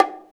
50 BONGO.wav